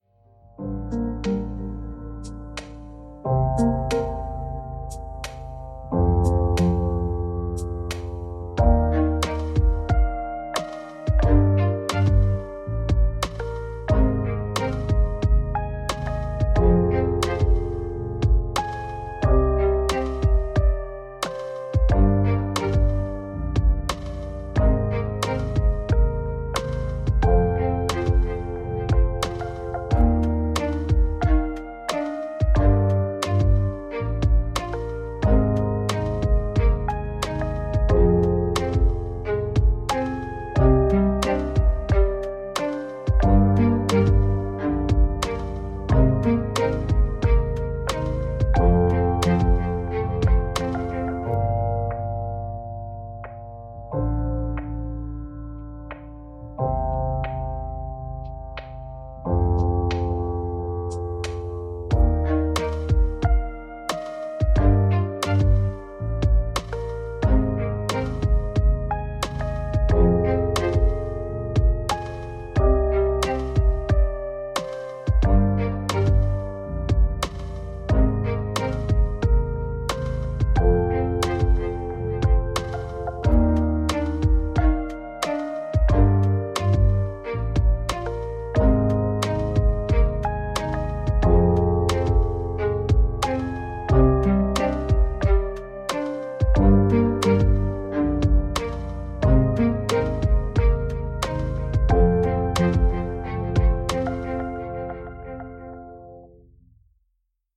Musique chill libre de droit pour vos projets.